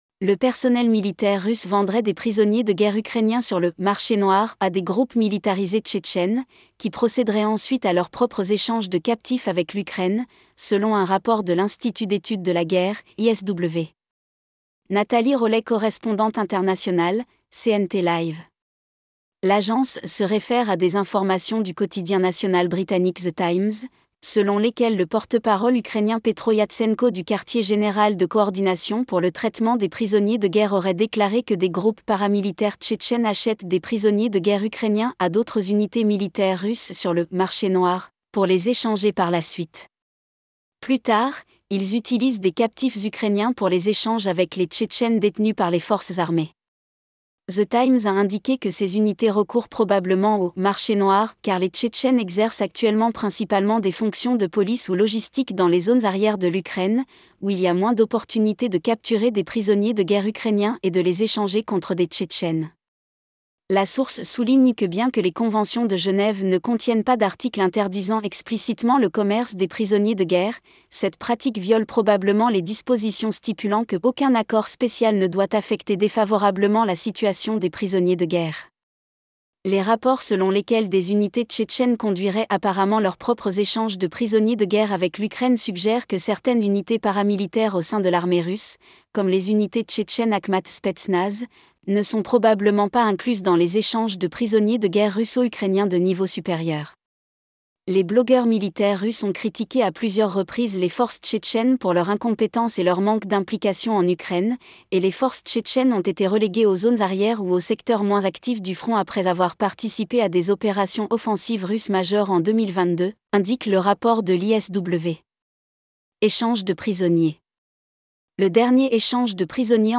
correspondante Internationale